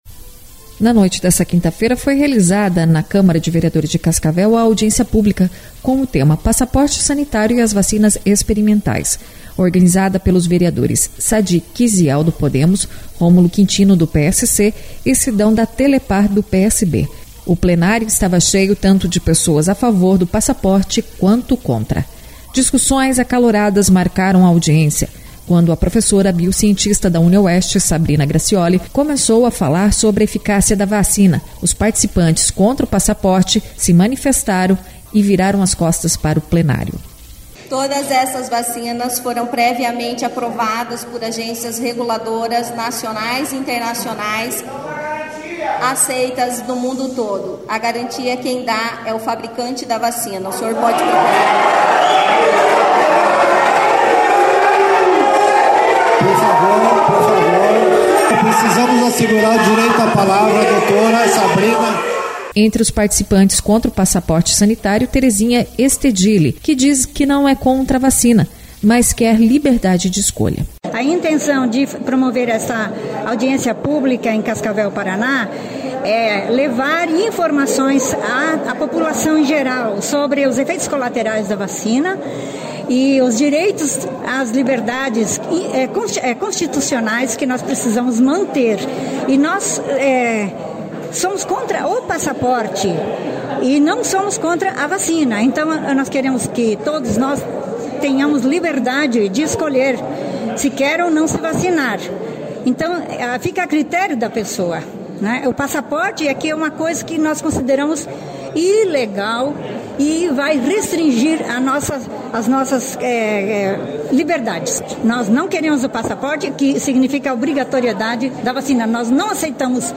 Audiência Pública sobre o Passaporte Sanitário é realizado na Câmara de vereadores